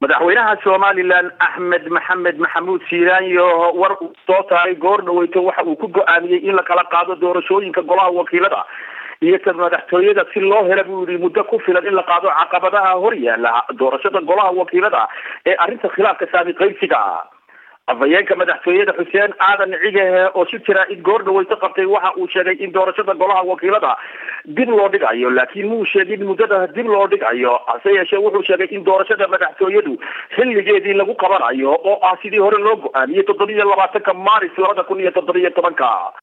Dhagayso Wariyaha BBC